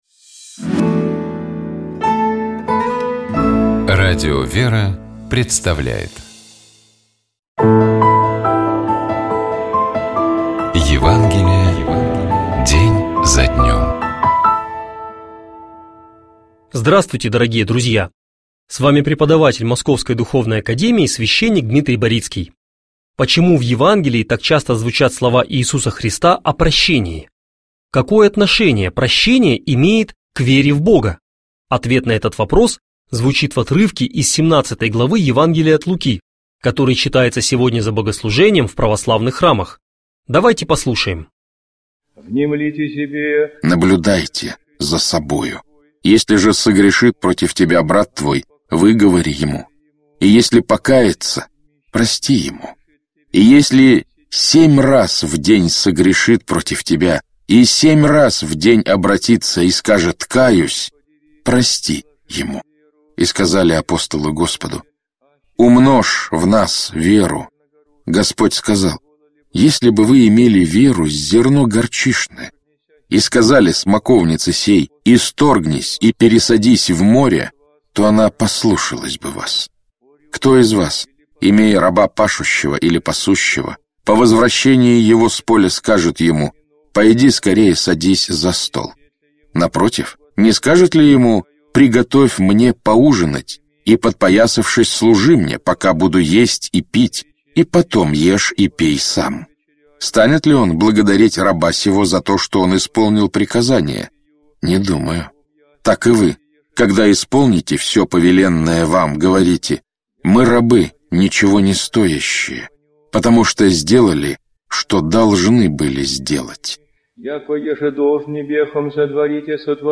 Читает и комментирует
священник